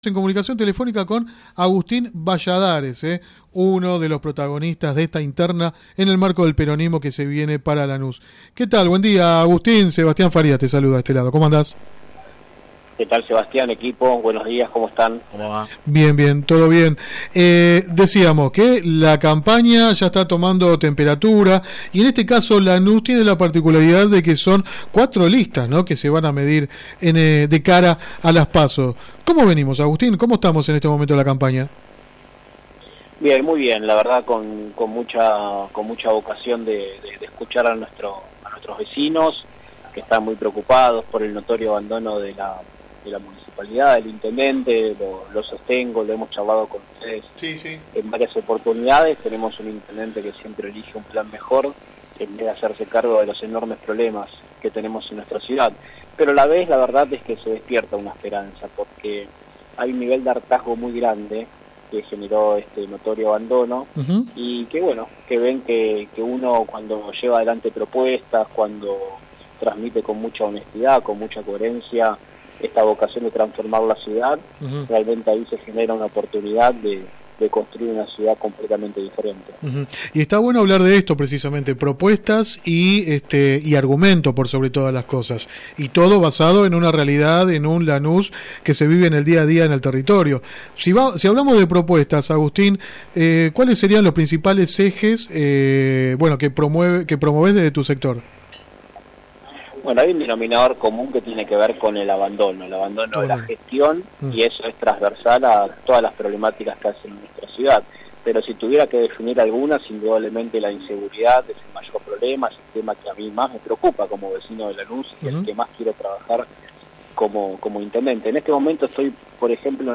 Click acá entrevista radial